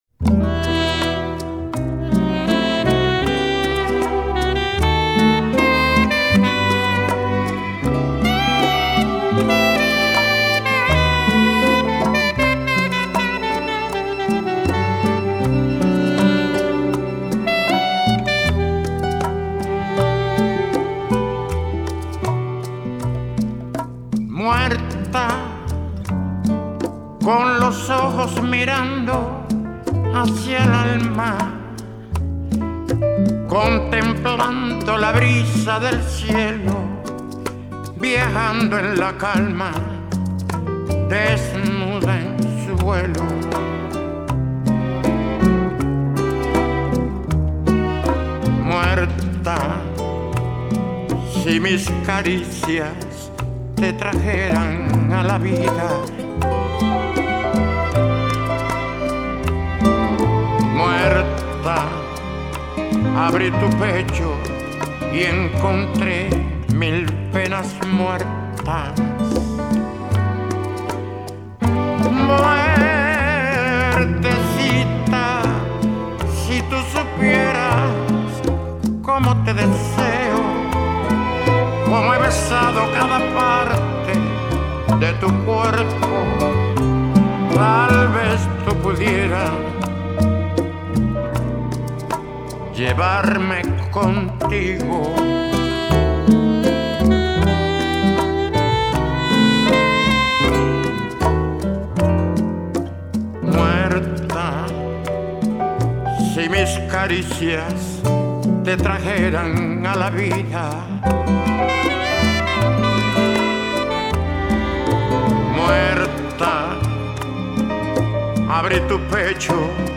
bolerazos